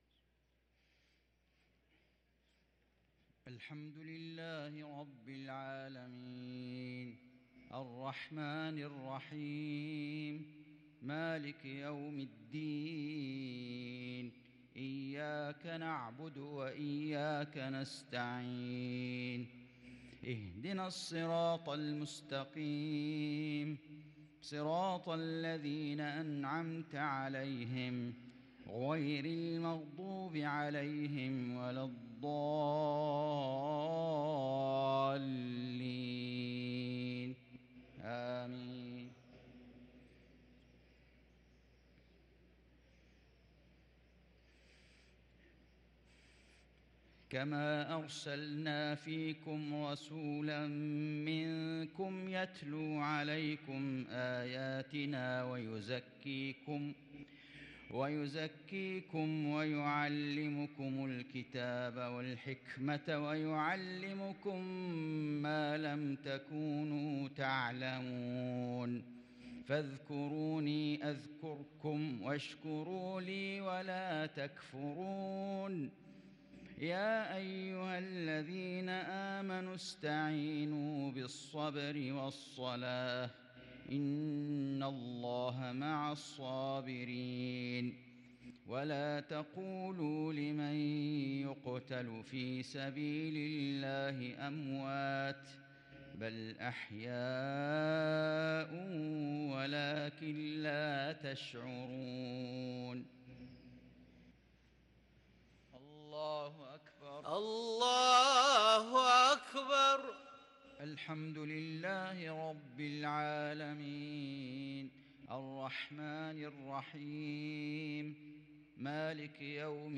صلاة المغرب للقارئ فيصل غزاوي 17 ربيع الأول 1444 هـ
تِلَاوَات الْحَرَمَيْن .